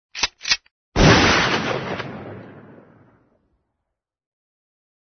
Play DISPAROS Y CARGANDO - SoundBoardGuy
disparos-y-cargando.mp3